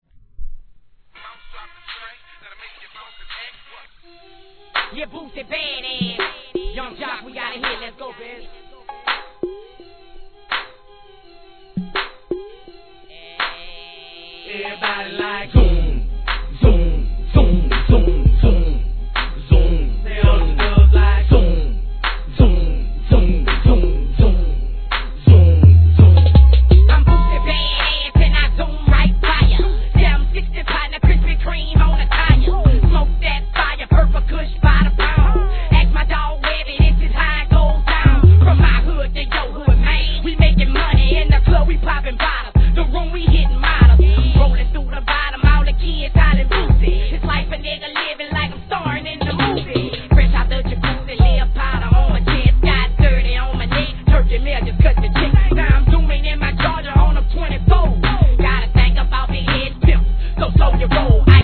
HIP HOP/R&B
「ズン、ズン、ズンズン」いうキャッチーなサビも印象的でHITしそうです!!!!!